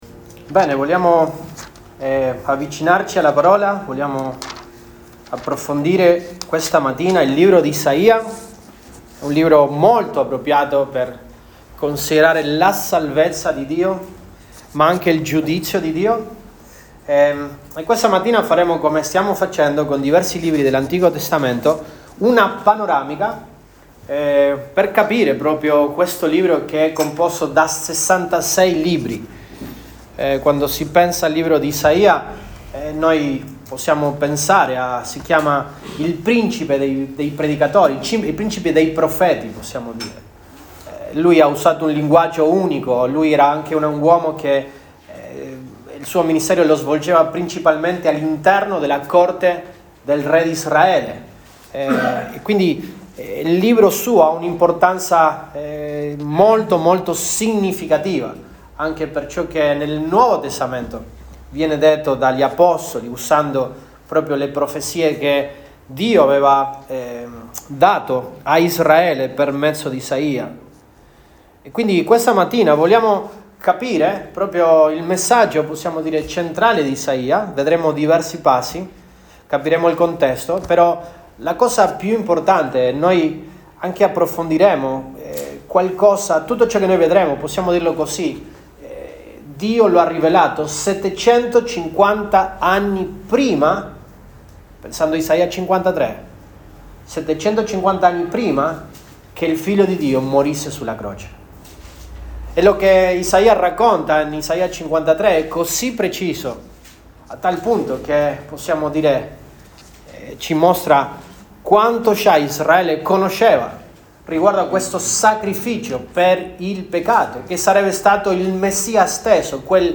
Apr 20, 2025 Come purificherà il Santo d’Israele il suo popolo da ogni empietà MP3 Note Sermoni in questa serie Come purificherà il Santo d'Israele il suo popolo da ogni empietà.